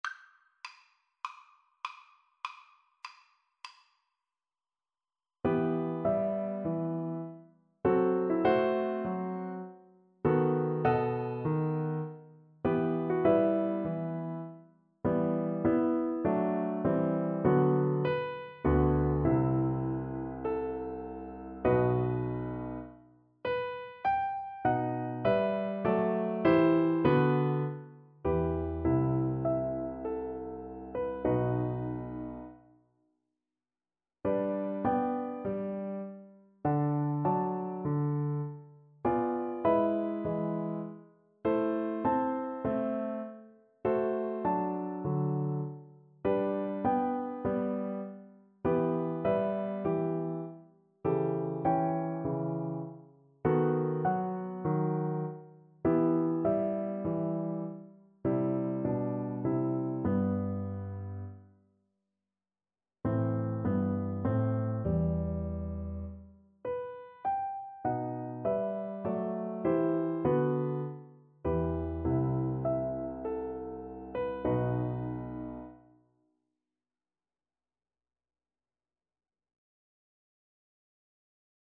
Largo =c.100